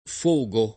fogo
[ f 1g o ]